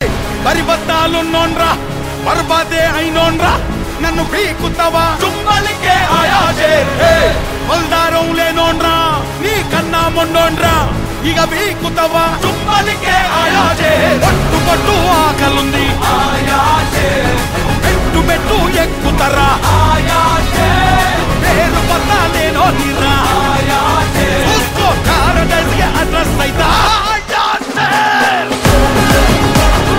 powerful and trending
loud, clear sound
mass ringtone download